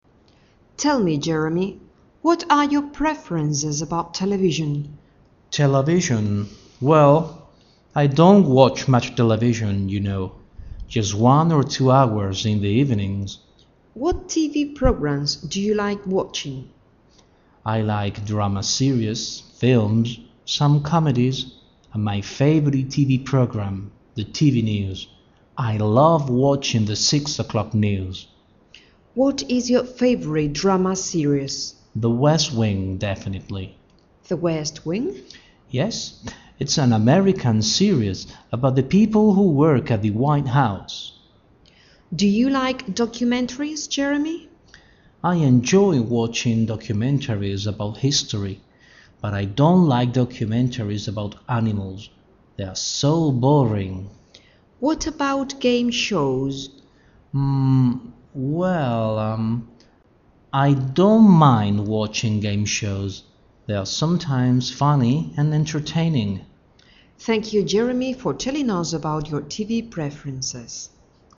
Hagamos un Listening o audición.